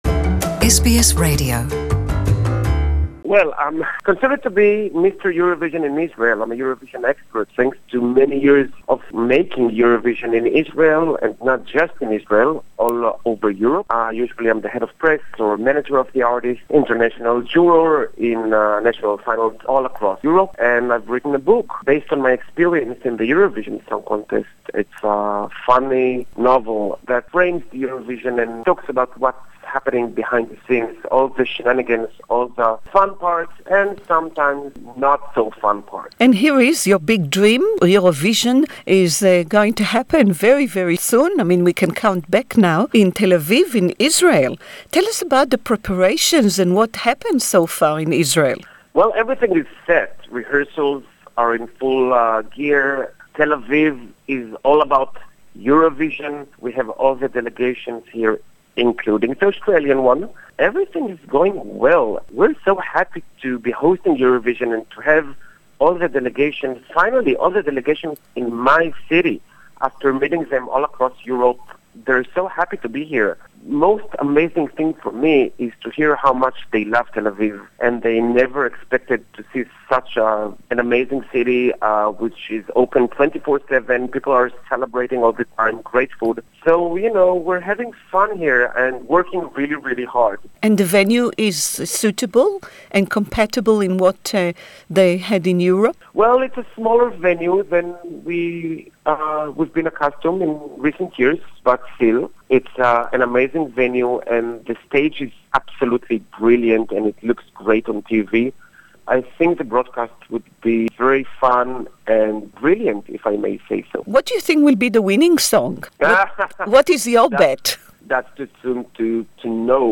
Interview in English